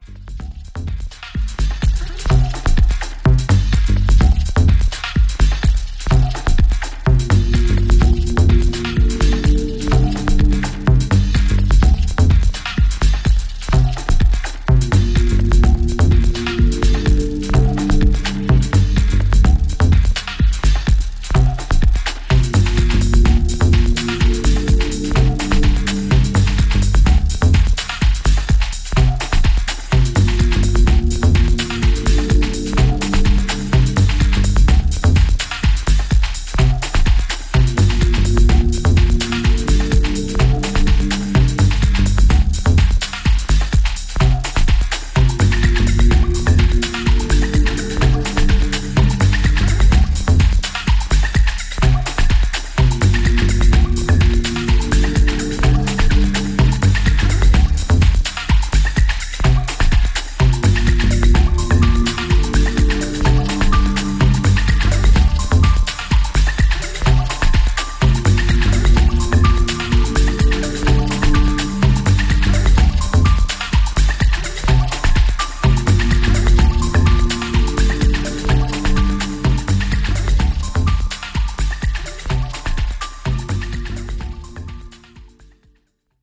futuristic techno cut with gentle, soul infused pads